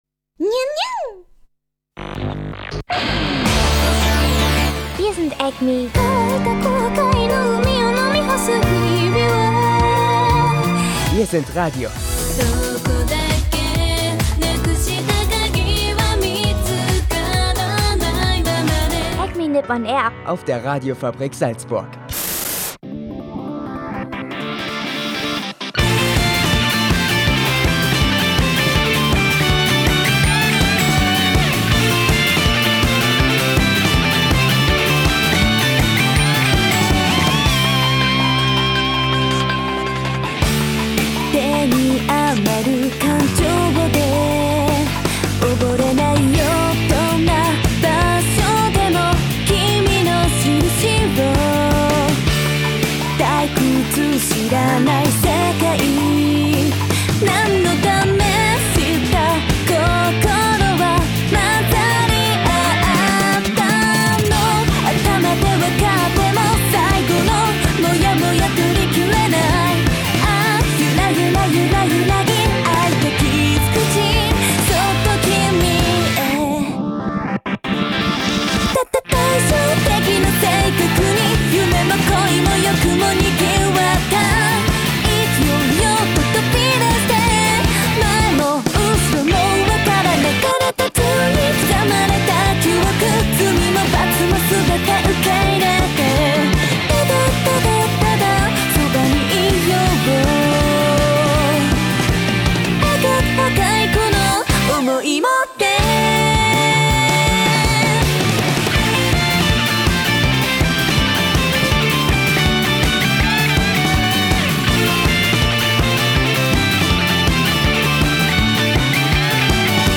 Auch diese Woche gehts noch um die Aninite, diese Woche mit folgenden Interviews:
Dazu Acme.Japan-News, Acme.Japan-Wetter und natürlich viel Animemusik